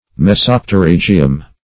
Search Result for " mesopterygium" : The Collaborative International Dictionary of English v.0.48: Mesopterygium \Me*sop`te*ryg"i*um\, n. [NL., fr. Gr. me`sos middle + ? a fin.]